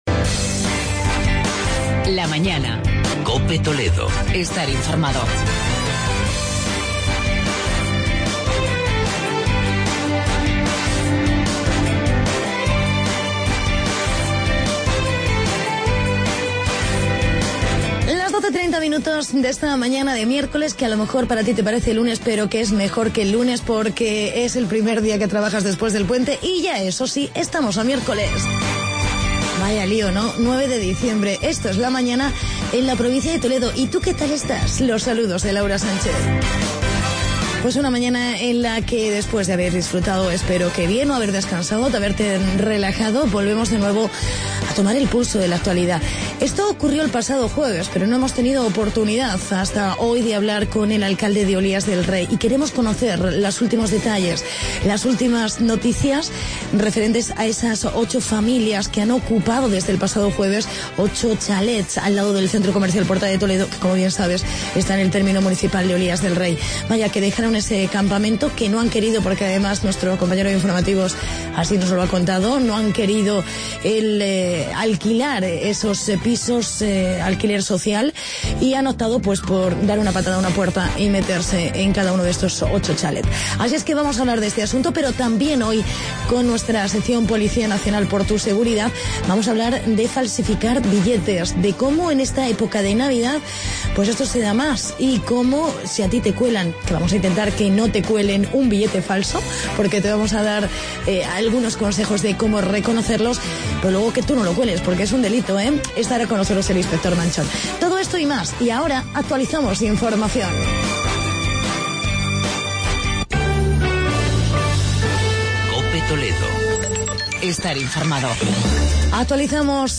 Entrevista con el alcalde de Olías del Rey